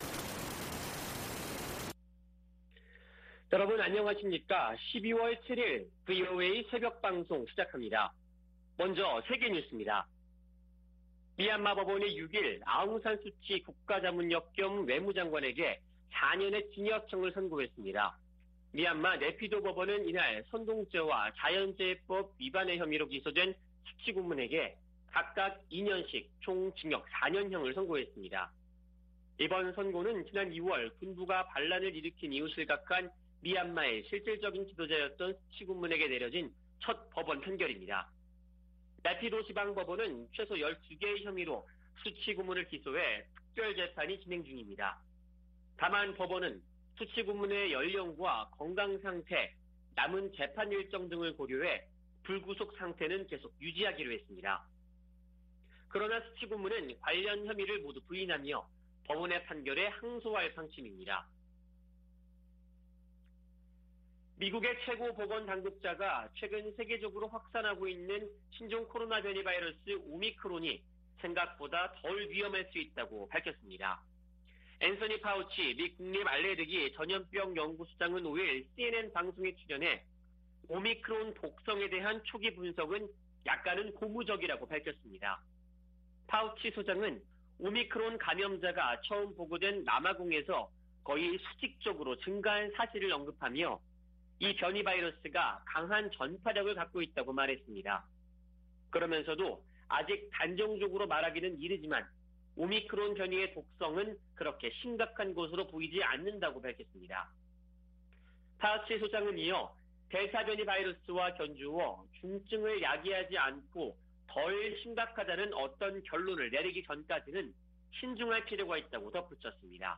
VOA 한국어 '출발 뉴스 쇼', 2021년 12월 7일 방송입니다. 중국이 한반도 종전선언 추진에 지지 의사를 밝혔지만 북한은 연일 미국을 비난하며 냉담한 태도를 보이고 있습니다. 유엔이 올해에 이어 내년에도 북한을 인도지원 대상국에서 제외했다고 확인했습니다. 미국 유권자 42%는 조 바이든 행정부 출범 이후 미북 관계가 악화한 것으로 생각한다는 조사 결과가 나왔습니다.